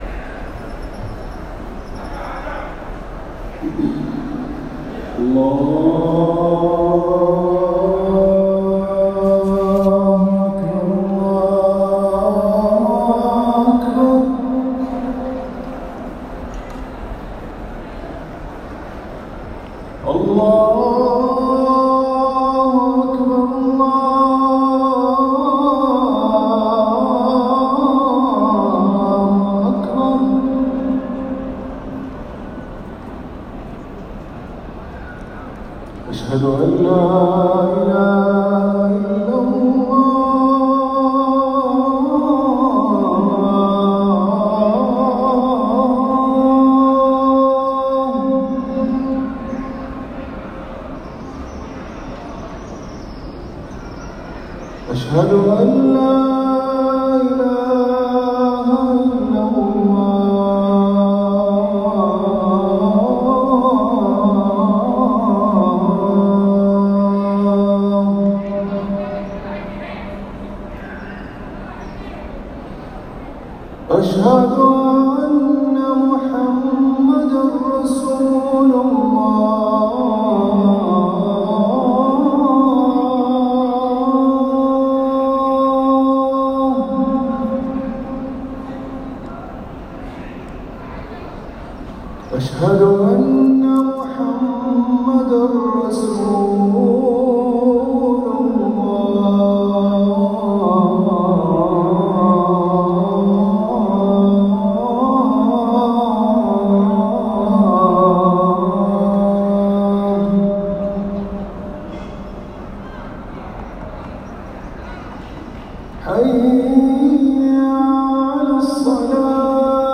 الأذان الأول لصلاة الفجر > ركن الأذان